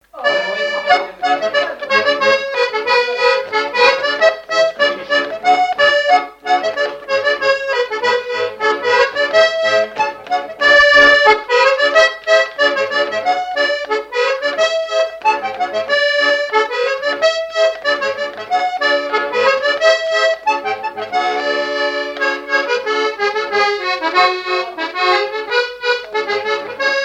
danse : scottich trois pas
Pièce musicale inédite